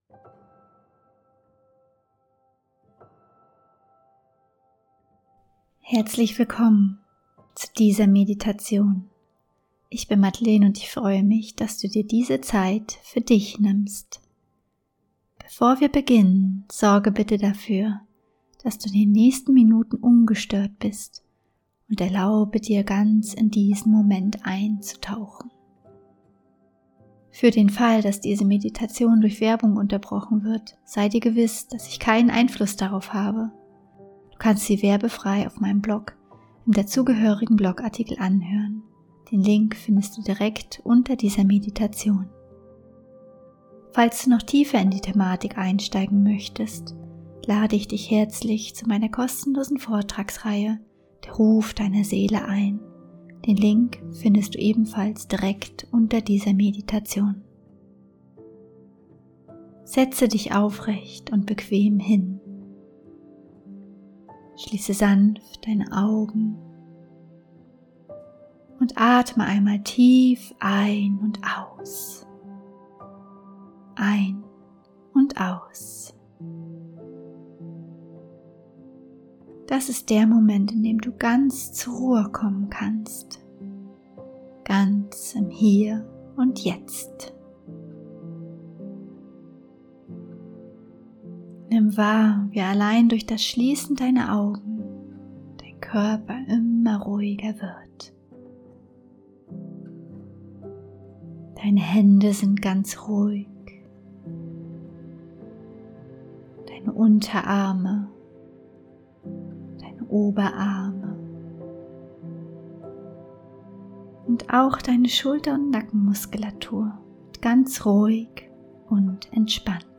15-Min geführte Meditation: Von der Sehnsucht zur Erfüllung – Die Aufgaben annehmen, die dich heilen ~ Heimwärts - Meditationen vom Funktionieren zum Leben Podcast
15_Min_gefuehrte_Meditation_von_Sehnsucht_zur_Erfuellung.mp3